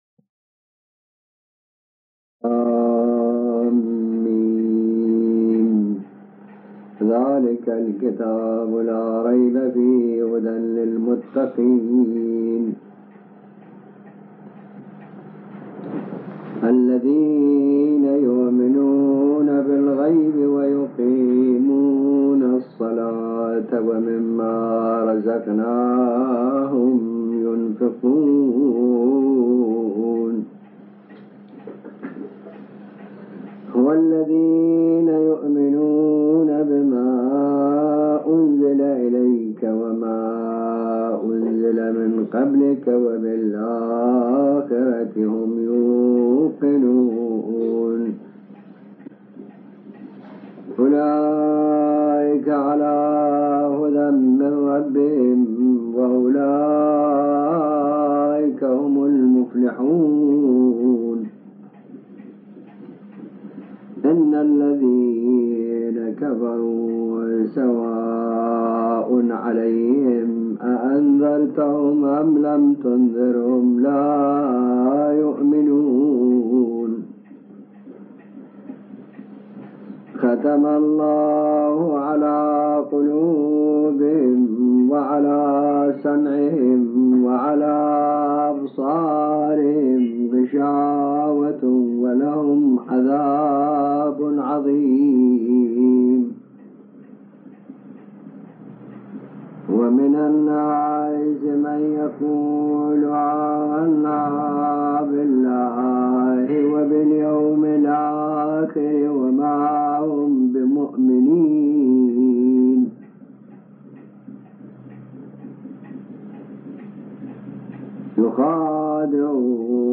قرائت قسمتی از سوره بقره